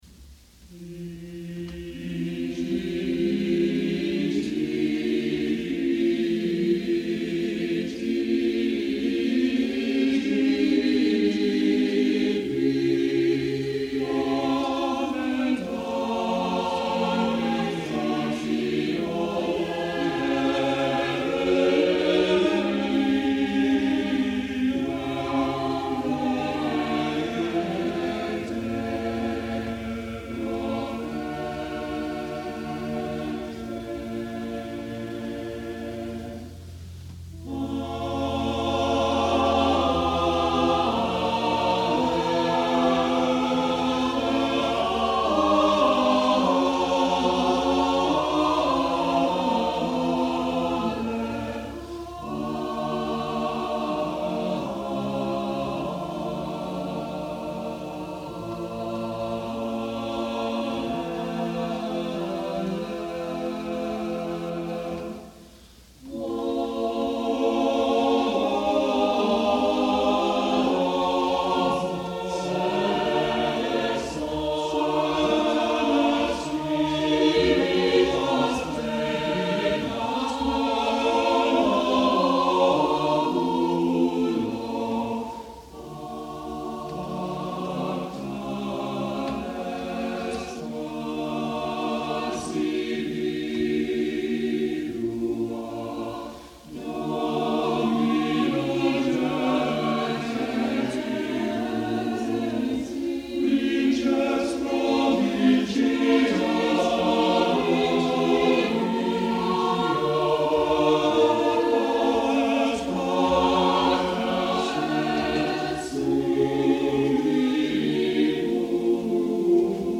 The few pieces heard here attest to his mastery of vocal polyphony as practiced especially by Cristobal de Morales in Spain, whose works are found in many Mexican sources.